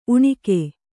♪ uṇike